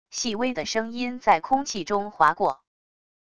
细微的声音在空气中滑过wav音频